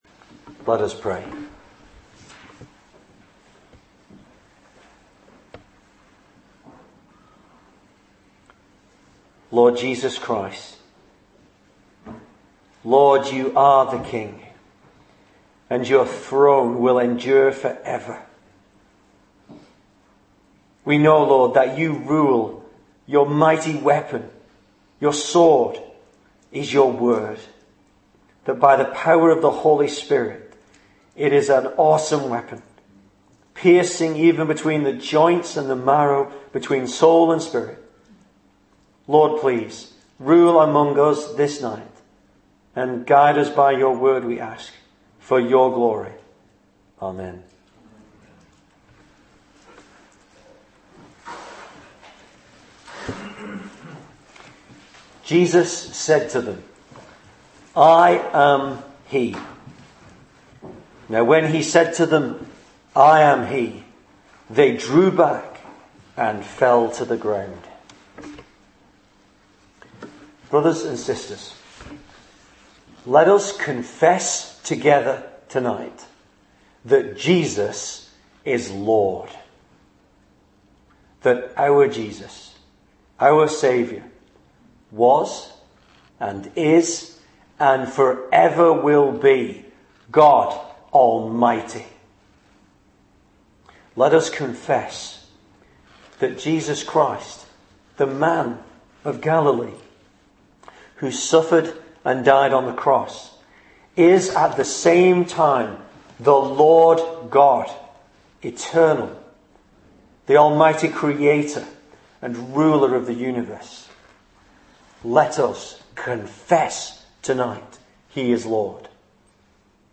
Easter Sermons